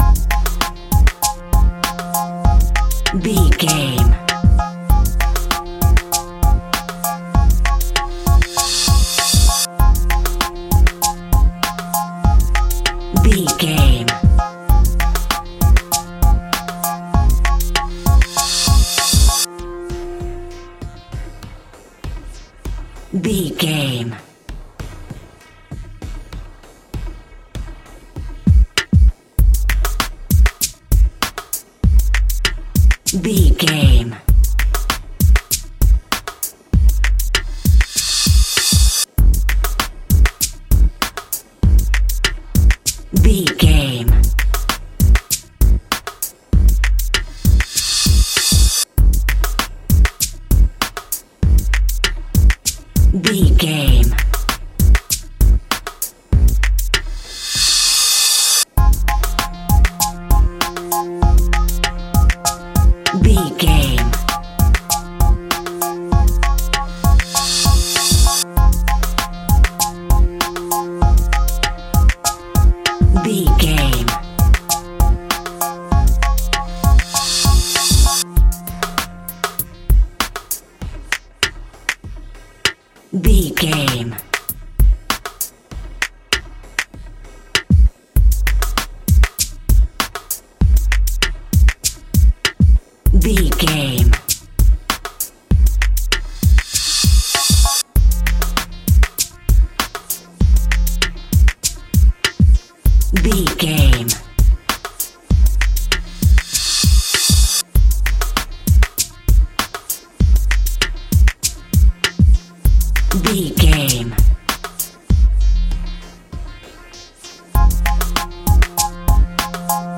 Beat for Hip Hop.
Aeolian/Minor
hip hop instrumentals
funky
groovy
east coast hip hop
electronic drums
synth lead
synth bass